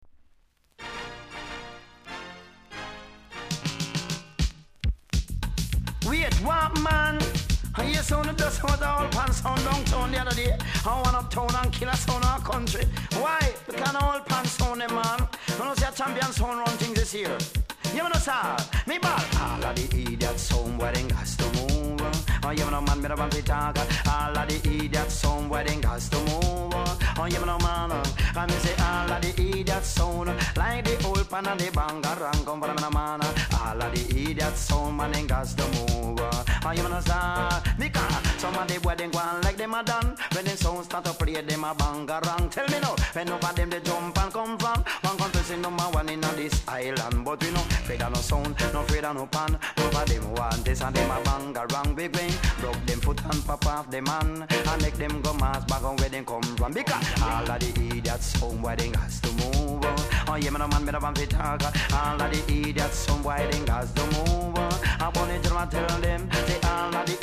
NICE DEEJAY!!